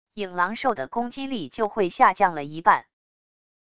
影狼兽的攻击力就会下降了一半wav音频生成系统WAV Audio Player